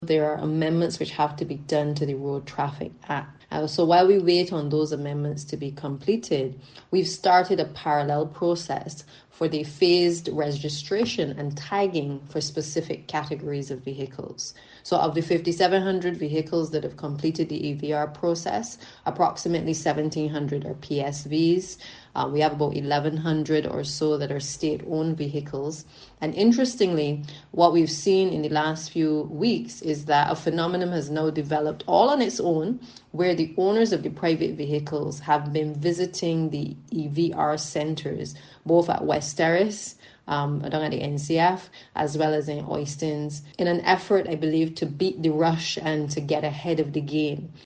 Minister of Transport, Works and Water Resources, Santia Bradshaw.